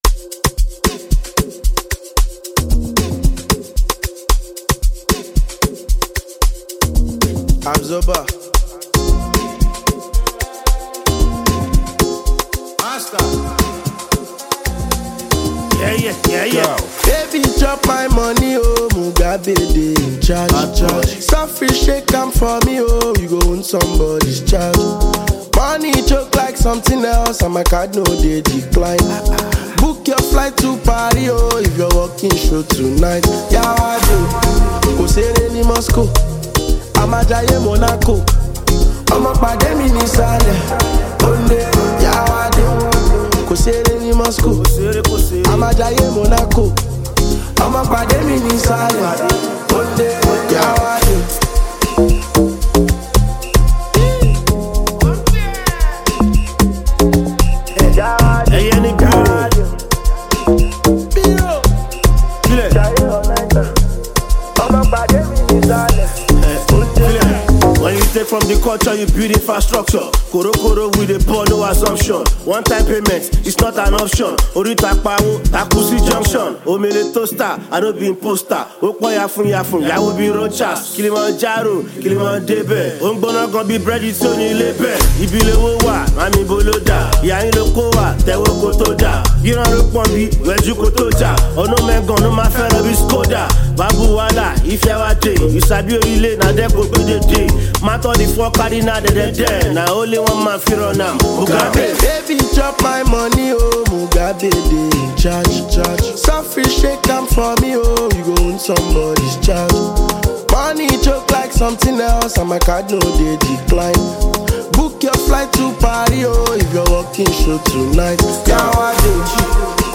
Nevertheless, this thriller will surely get you dancing.